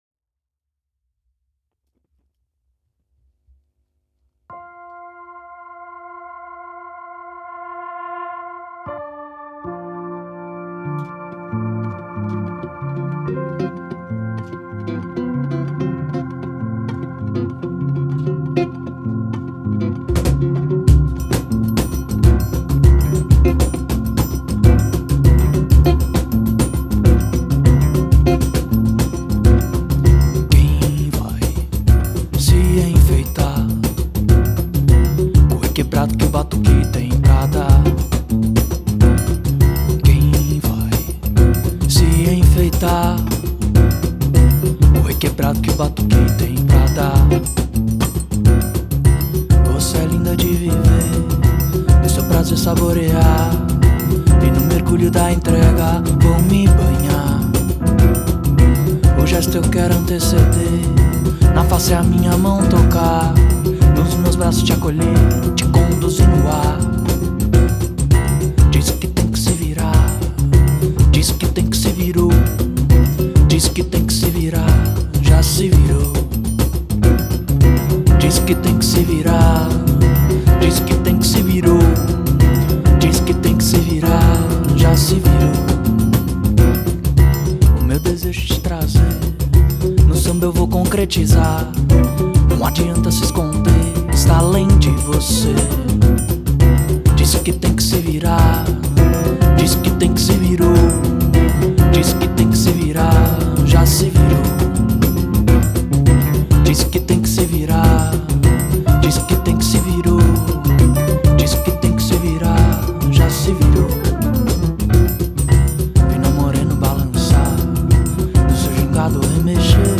Brazilian guitarist, singer and composer
pianist, piano accordionist and composer
multi-percussionist